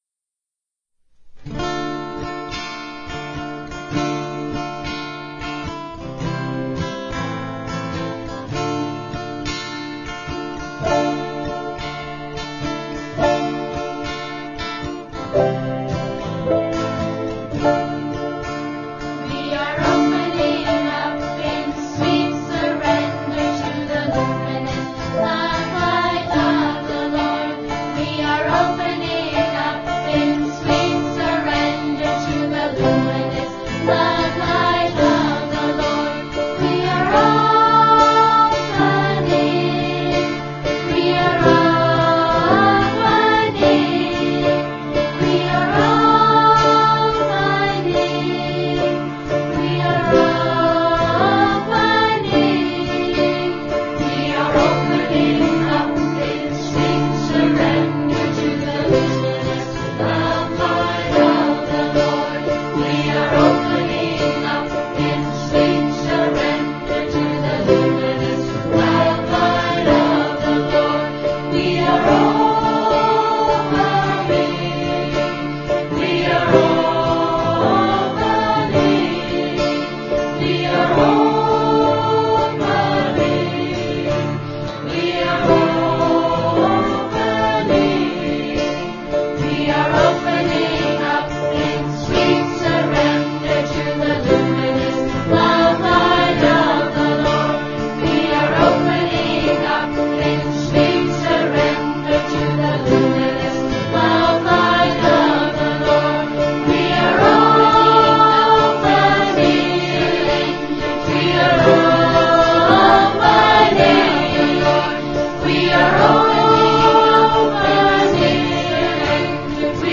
1. Devotional Songs
Minor (Natabhairavi)
8 Beat / Keherwa / Adi
2 Pancham / D
Lowest Note: n1 / B♭ (lower octave)
Highest Note: P / G
This song is best sung in unison.